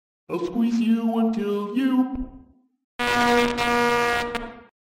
На этой странице собрана коллекция звуков и голосовых фраз Haggy Wagy.